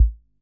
Index of /90_sSampleCDs/300 Drum Machines/EKO MusicBox-12